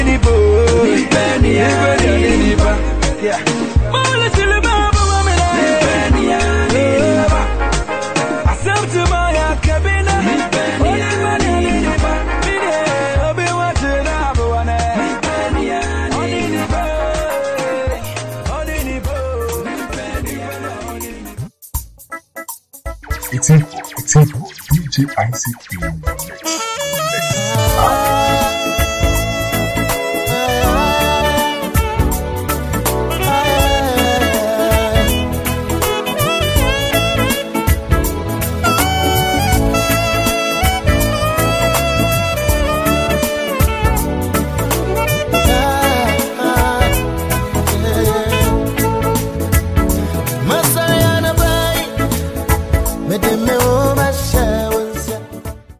Temes musicals